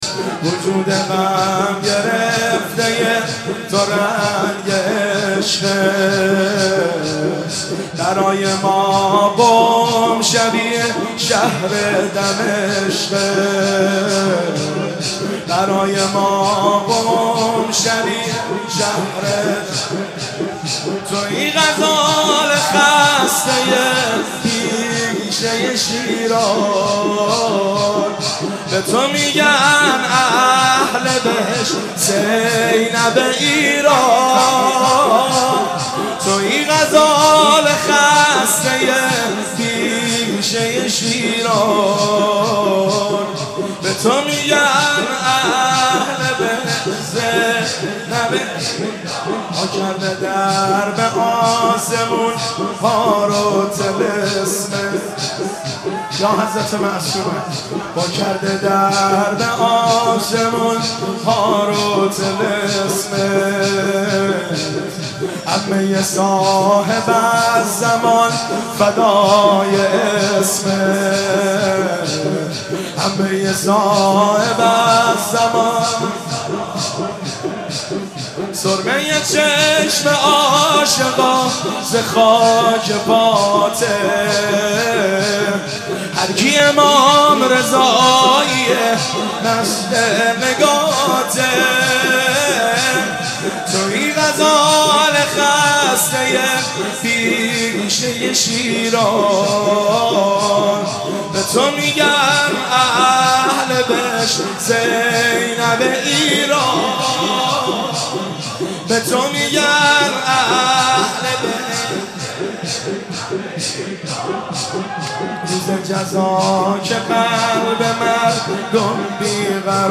مداحی وفات کریمه اهل بیت حضرت معصومه (س)